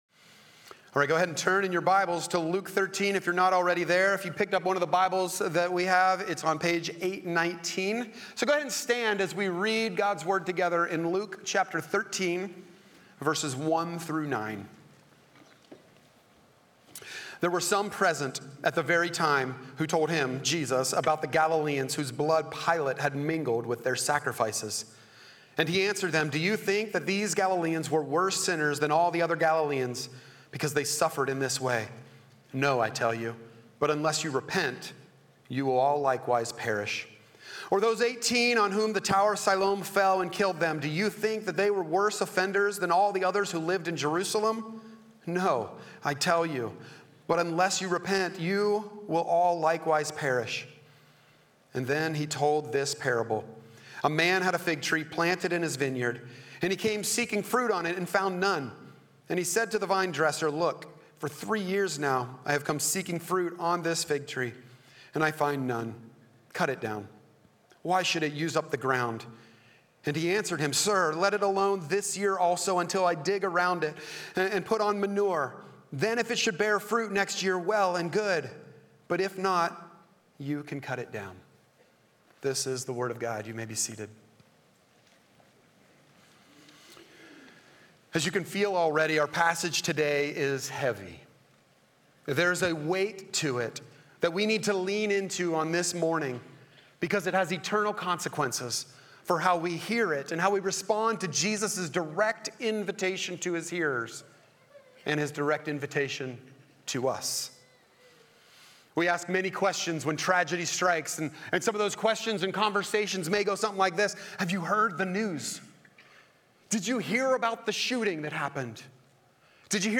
Sermon Slides Worship Program Grace Groups Study Guide